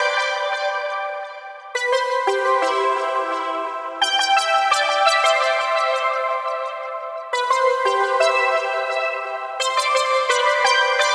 Index of /DESN275/loops/Loop Set - Futurism - Synthwave Loops
BinaryHeaven_86_C_SynthLead.wav